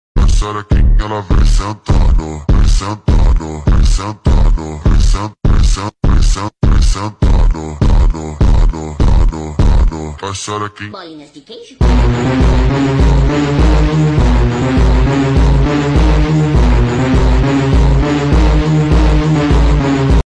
Supra MK5 Mp3 Sound Effect